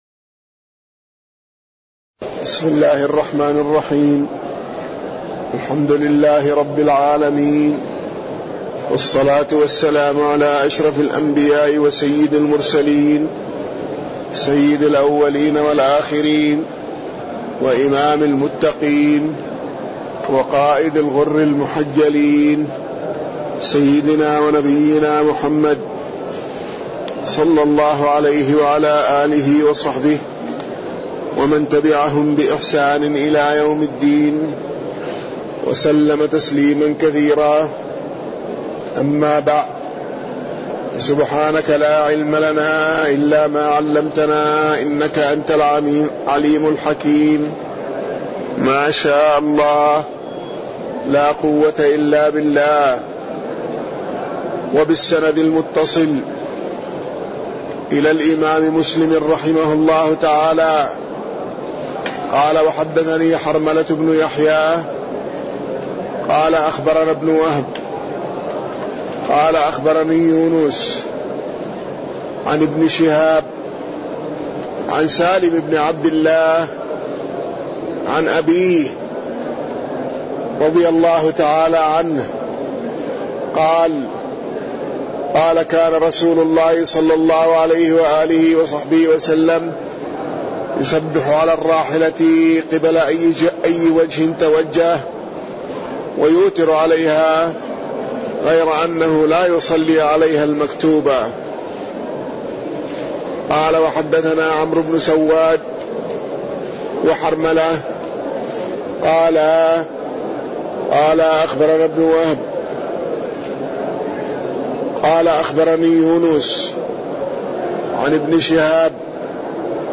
تاريخ النشر ٨ رجب ١٤٣٠ هـ المكان: المسجد النبوي الشيخ